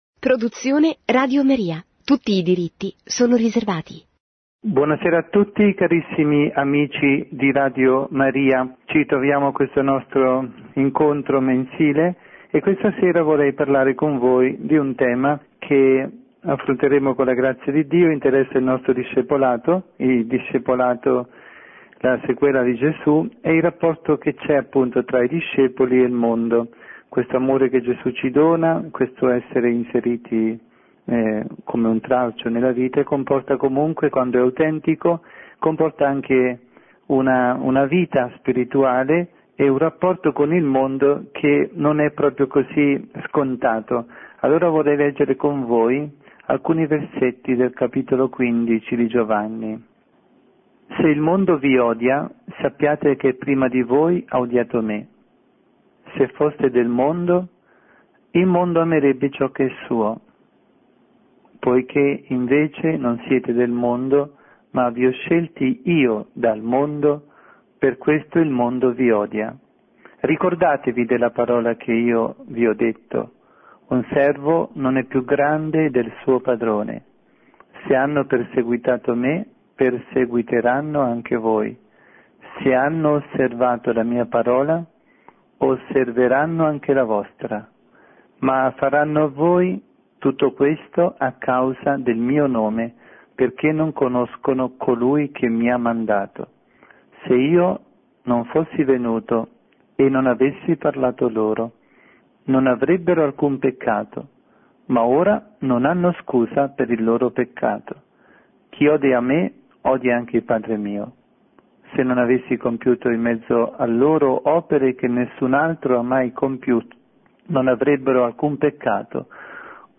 Catechesi
trasmessa in diretta su RadioMaria